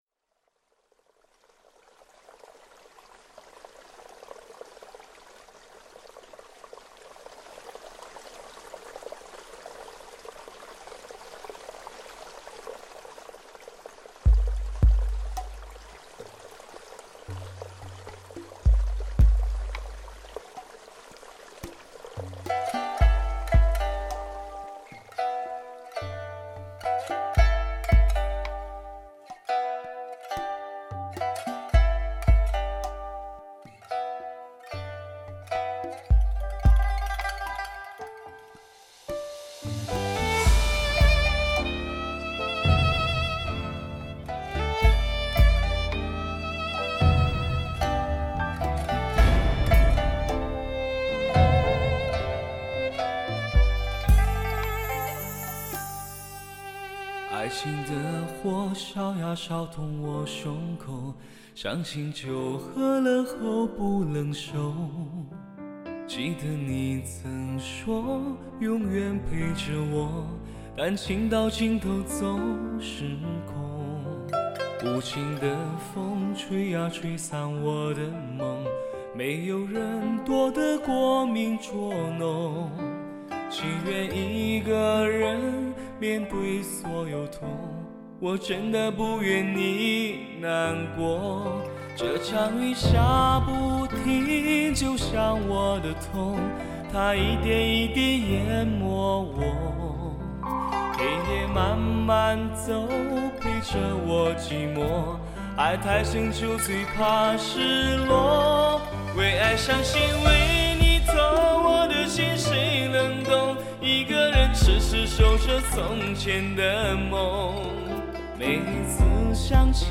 “被上帝亲吻过”的最完美人声，
顶级的DTS-ES声效制作，成就最完美的人声精选。
完美声线、深厚情感与专业录音制作技术，